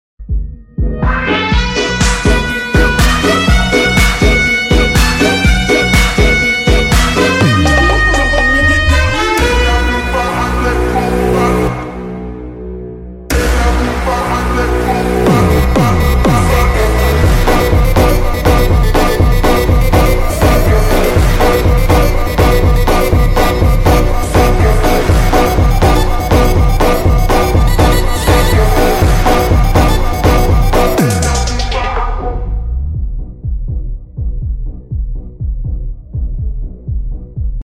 Sound serem nih 💀cr